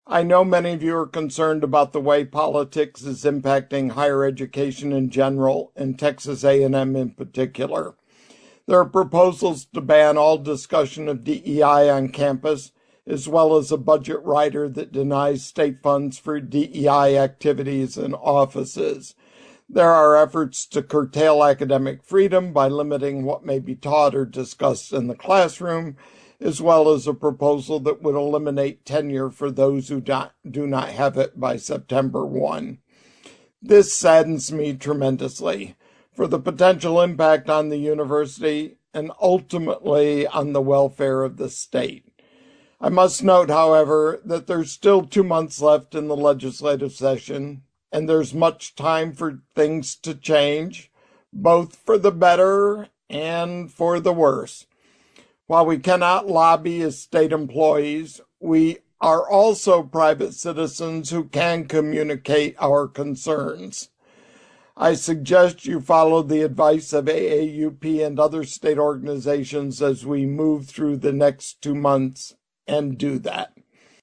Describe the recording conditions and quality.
Screen shot from a Texas A&M faculty senate video conference meeting.